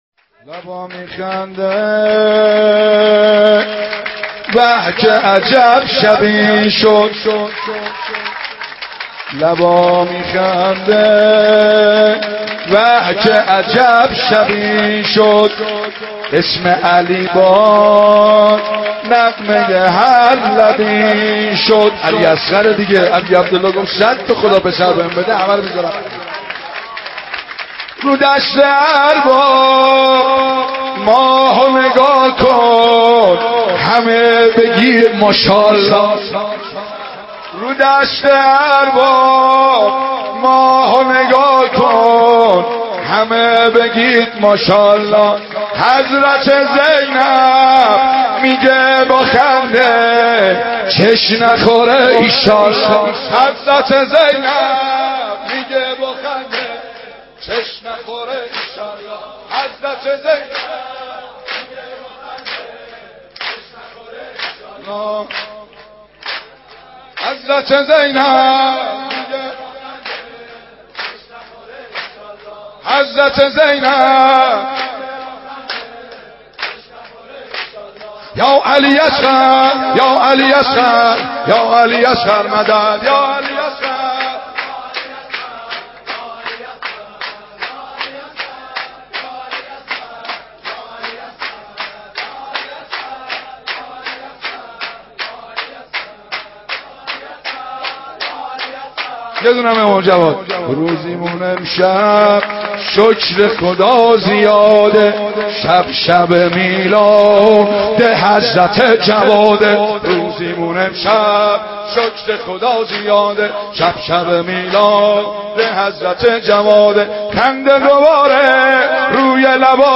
سرود ولادت حضرت علی اصغر(ع)، امام جواد(ع)